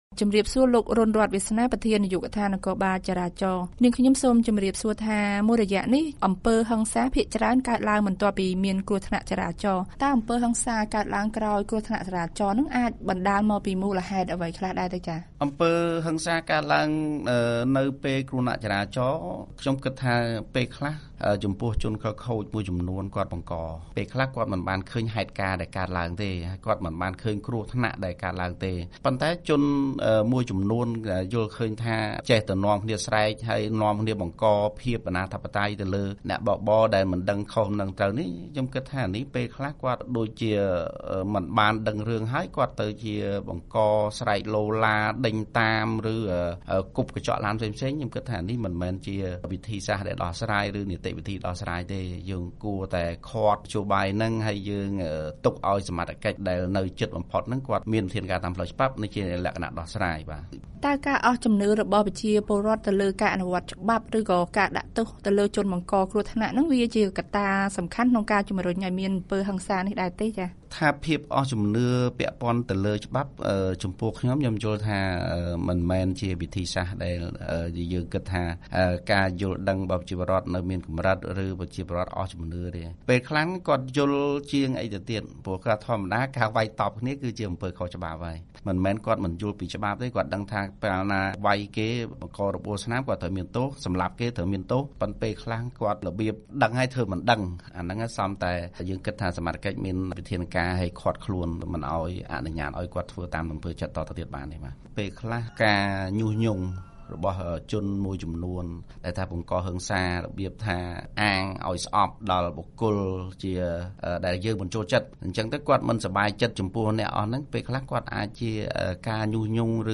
បទសម្ភាសន៍ VOA៖ មន្រ្តីខាងចរាចរណ៍អះអាងថា សមត្ថកិច្ចកំពុងទប់ស្កាត់អំពើហិង្សារឿងគ្រោះថ្នាក់ចរាចរណ៍